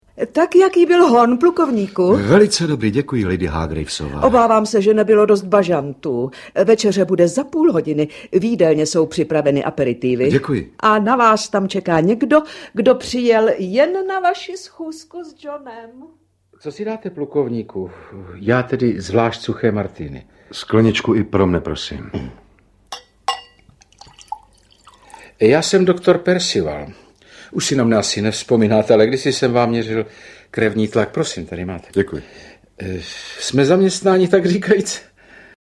Rozhlasová dramatizace špionážního románu.
Ukázka z knihy